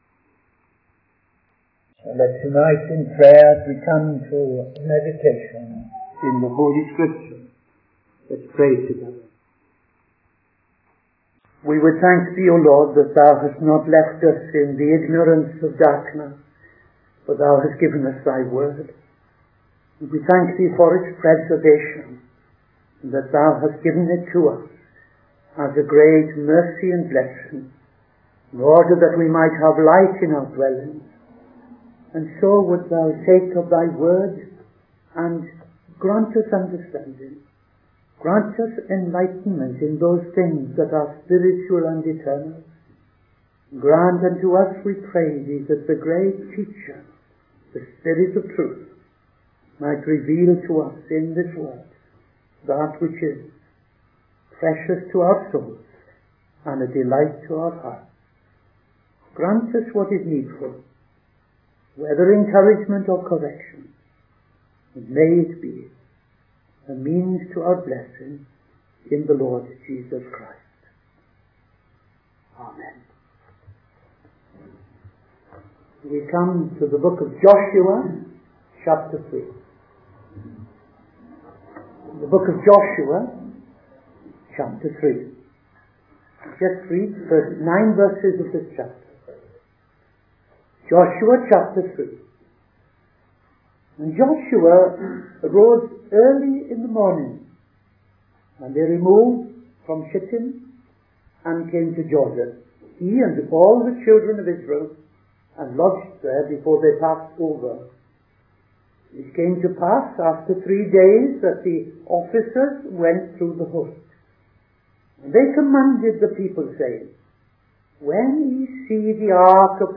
Midday Sermon - TFCChurch
Midday Sermon 9th November 2025